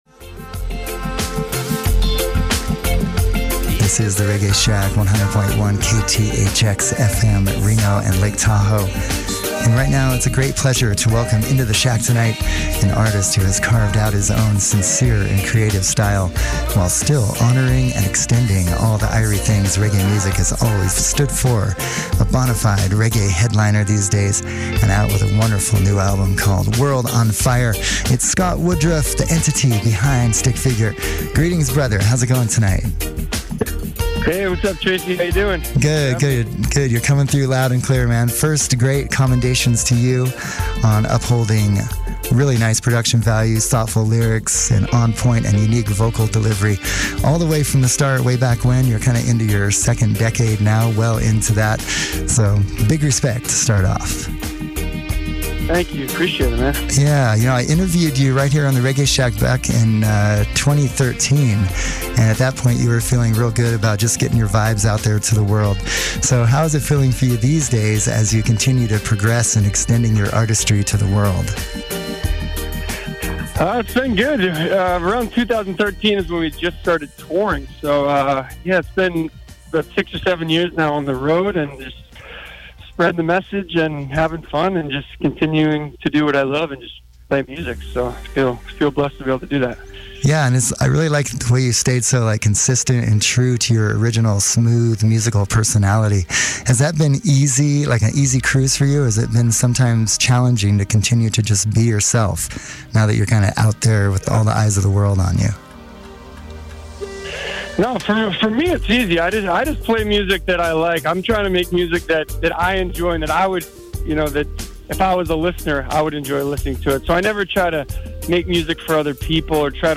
KTHX / Reggae Shack Interview: Stick Figure